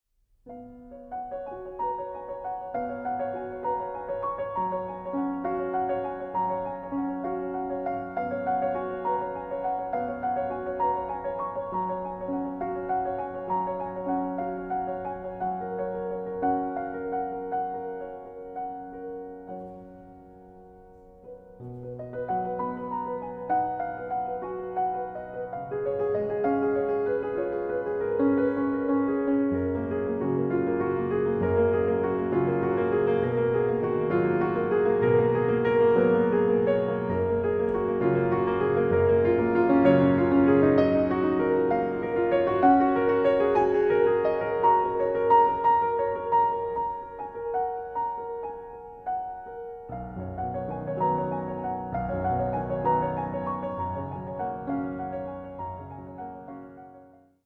piano
Recorded 16 and 17 October 2012 at St George's, Bristol, UK